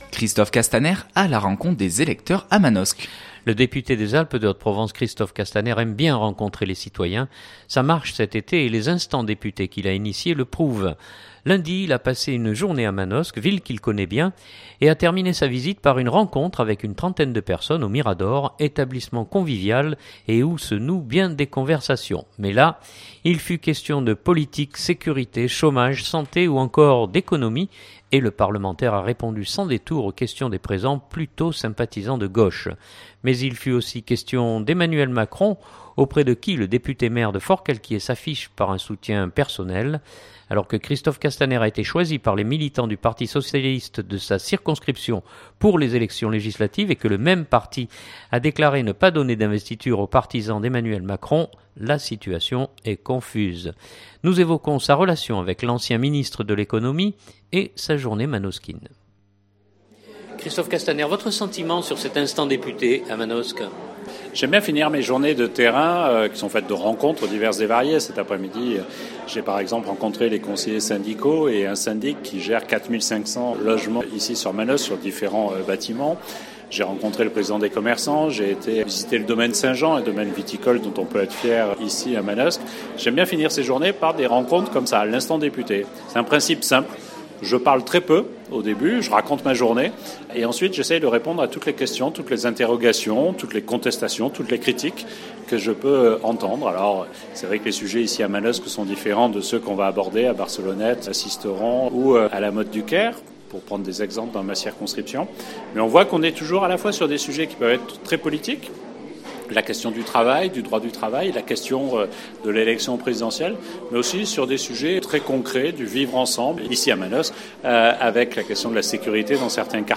Journal du 2016-12-15 Castaner.mp3 (4.07 Mo)